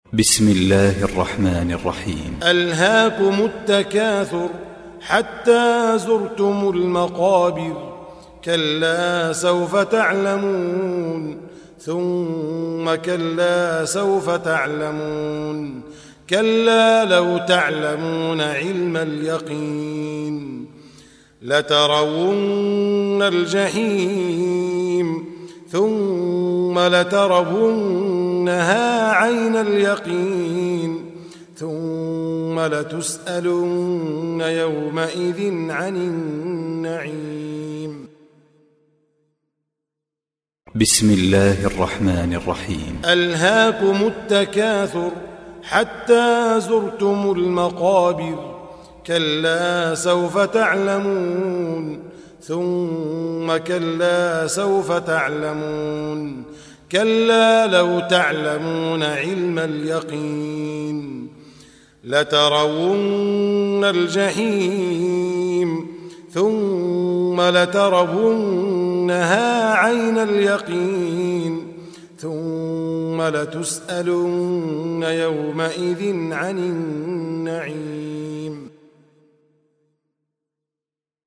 التكاثر القارئ: فضيلة الشيخ مجموعة مشائخ وعلماء الصنف: تلاوات تاريخ: الأربعاء 03 شوال 1438 هـ الموافق لـ : 28 جوان 2017 م رواية : قالون عن نافع الحجم:713.7K المدة :00:00:41 حمله :124 سمعه :213 سماع التلاوة تحميل التلاوة